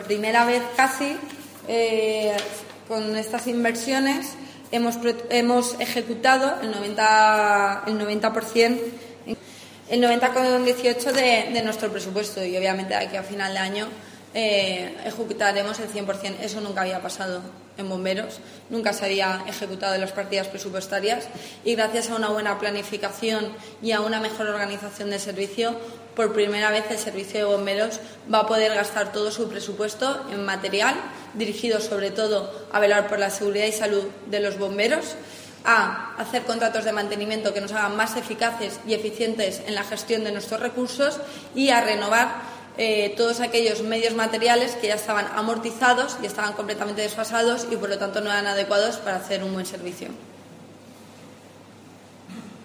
• La concejala Sandra Gómez durante la rueda de prensa.